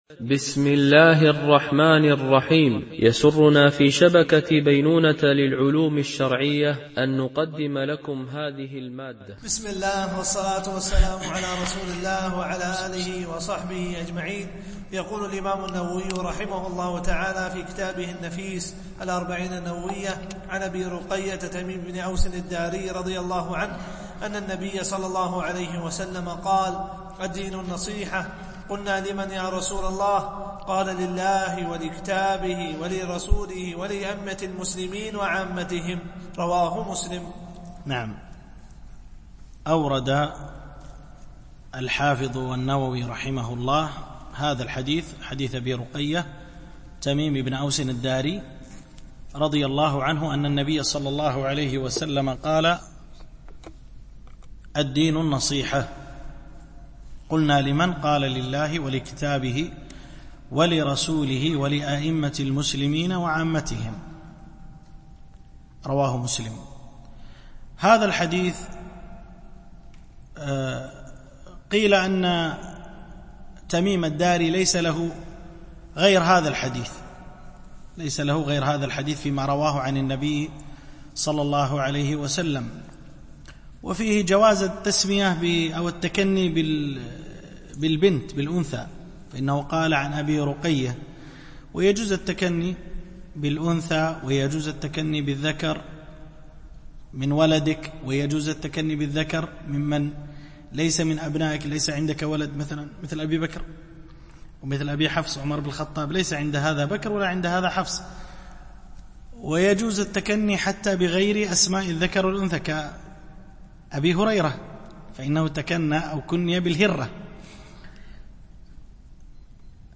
شرح الأربعين النووية - الدرس 5 (الحديث 7-8)